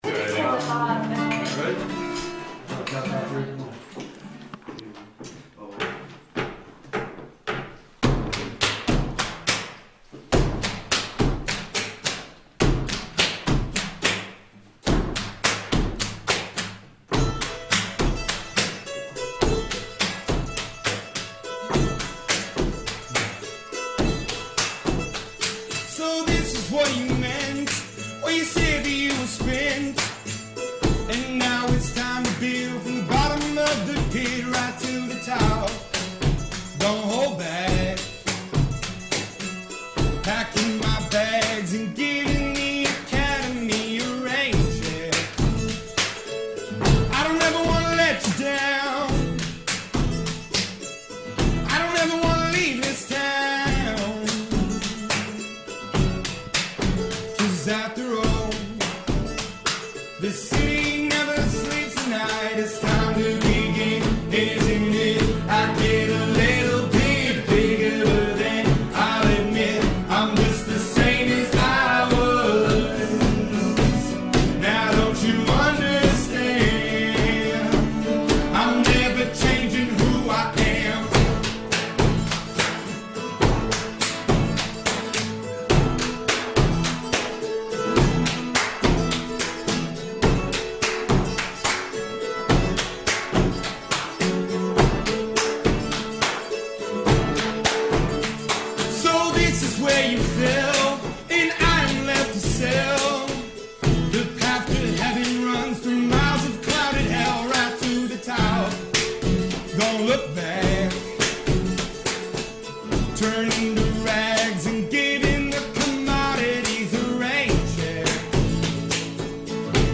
Its an acoustic breakdown.